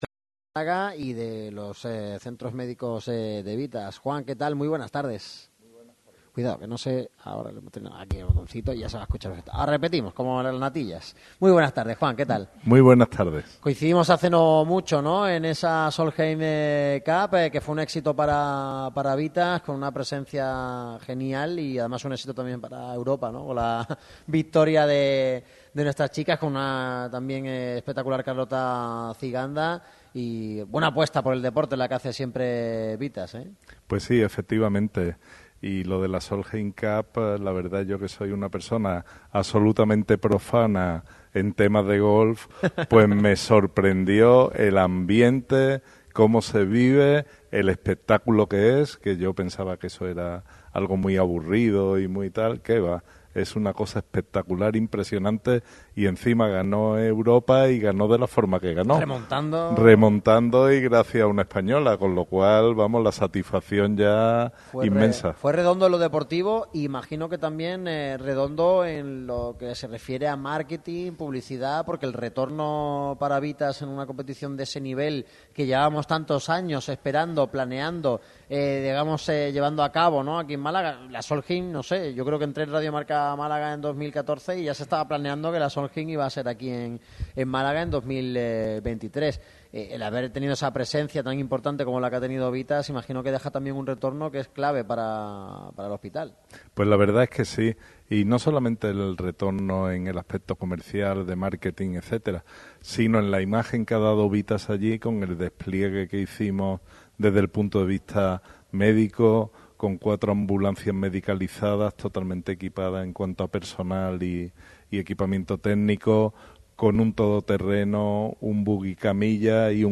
El micrófono rojo de Radio MARCA Málaga se desplaza hoy a las instalaciones de Vithas Xanit Salud en Av. de los Argonautas, en la localidad malagueña de Benalmádena. Toca hablar de salud pero también de la evolución, crecimiento y gran experiencia que atesora un gigante como el grupo Vithas.